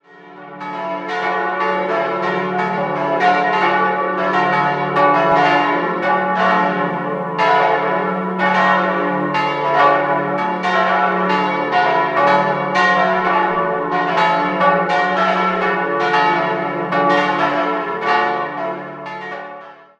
Jahrhundert, die in der Barockzeit grundlegend umgestaltet wurde. 4-stimmiges Geläute: d'-e'-fis'-a' Die Glocken 1, 3 und 4 wurden 1948 von Karl Hamm in Regensburg gegossen, Glocke 2 stammt ebenfalls von Hamm aus dem Jahr 1911.